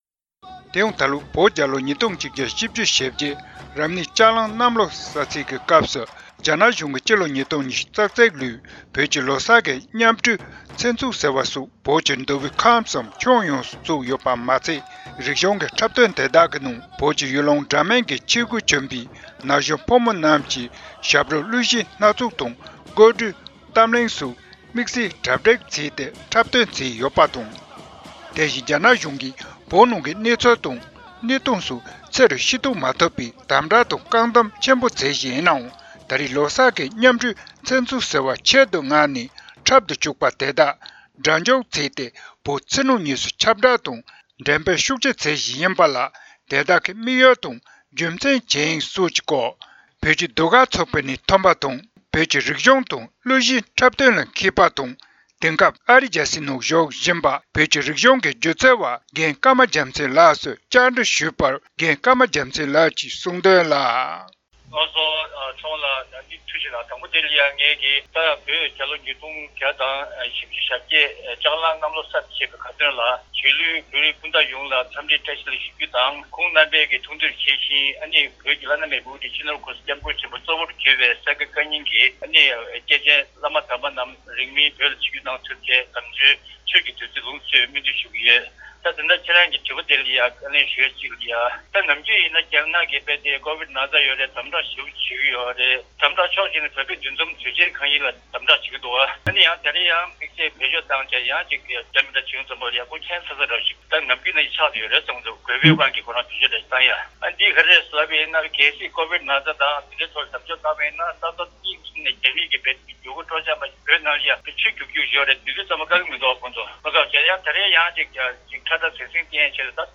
བོད་ཀྱི་རིག་གཞུང་དང་གླུ་གཞས་དང་འབྲེལ་བའི་མི་སྣ་སོགས་ལ་བཅའ་འདྲི་ཞུས་ནས་ཕྱོགས་བསྒྲིགས་བྱས་པ་